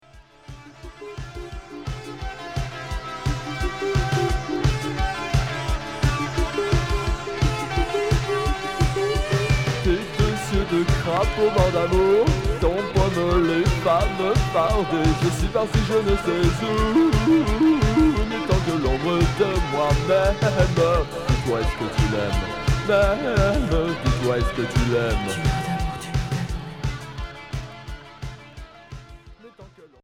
New wave rock